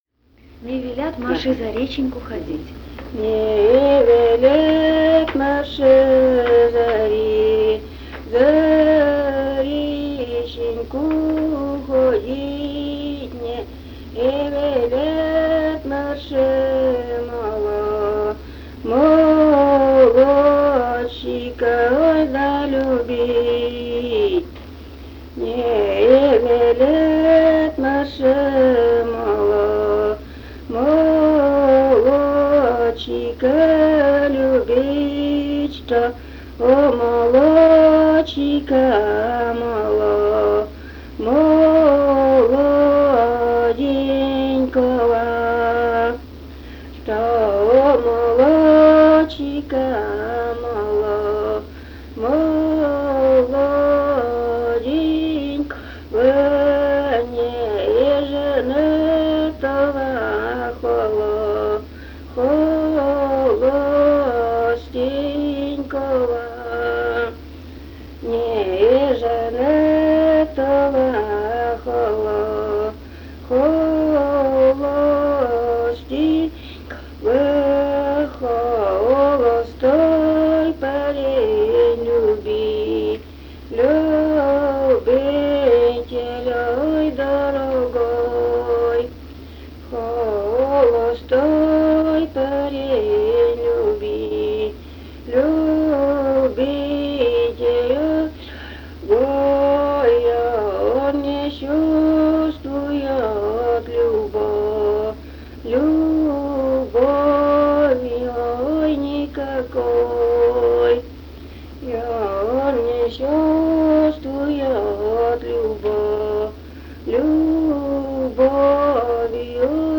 Этномузыкологические исследования и полевые материалы
Пермский край, г. Оса, 1968 г. И1074-17